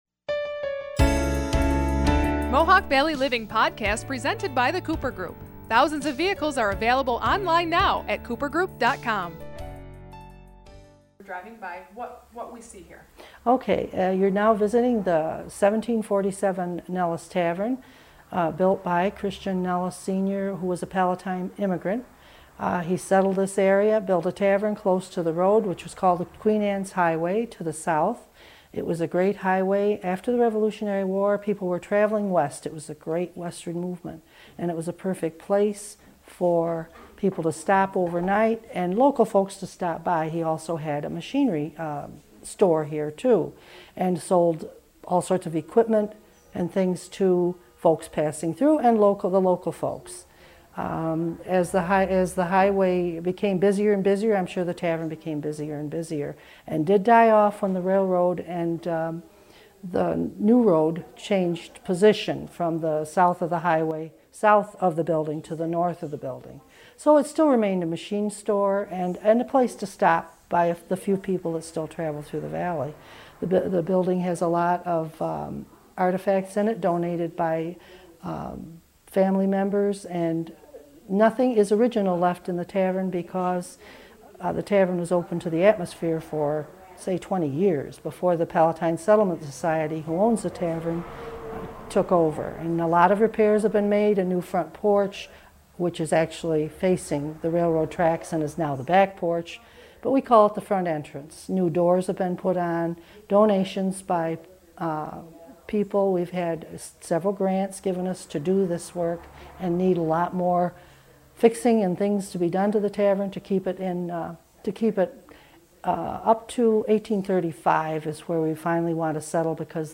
Extended interview